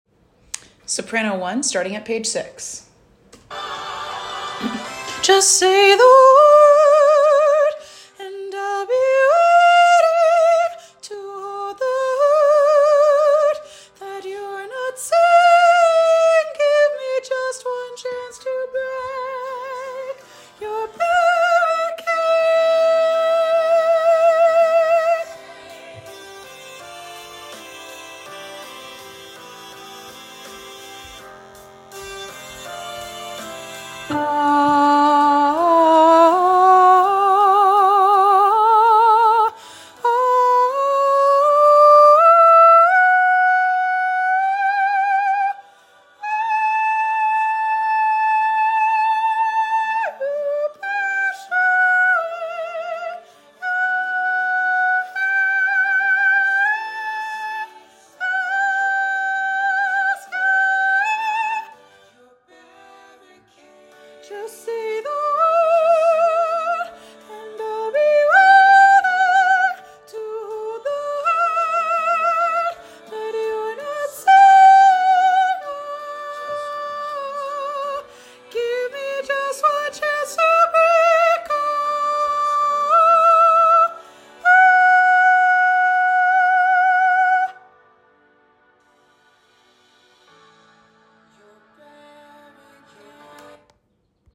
with lush harmonies, tall chords, and an epic conclusion.
Sop 1